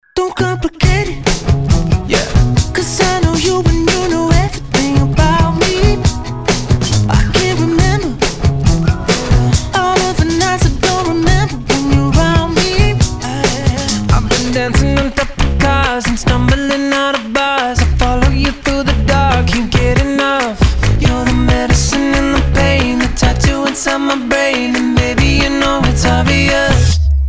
Категория - клубные.